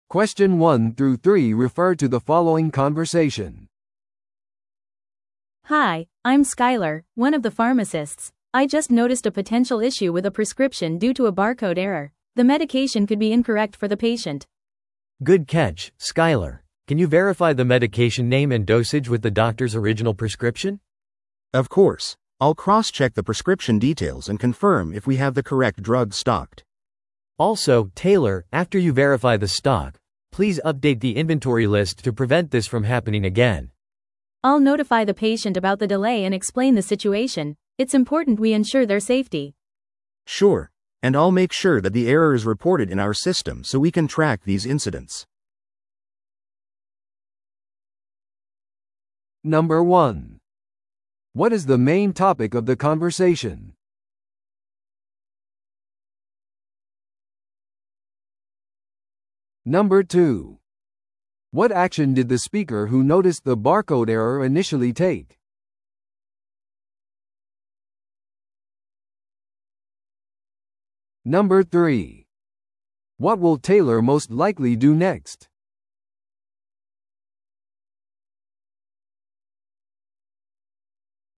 No.1. What is the main topic of the conversation?